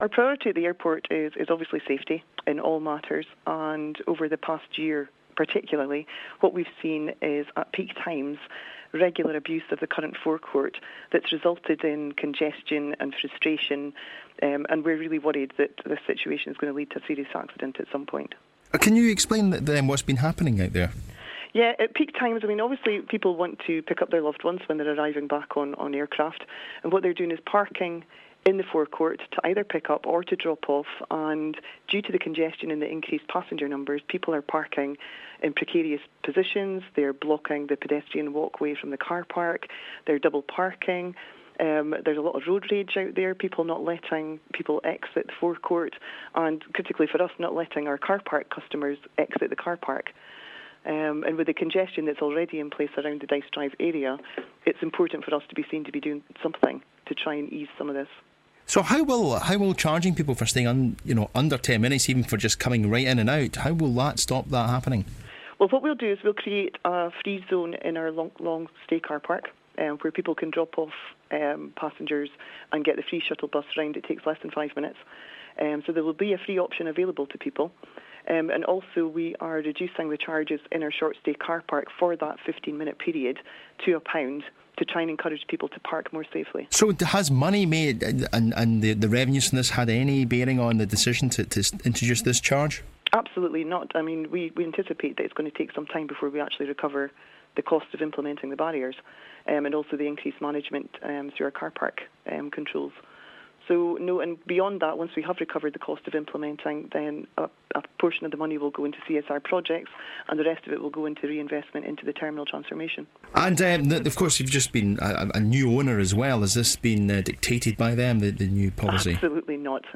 NEWS: Drop off charge for Aberdeen Airport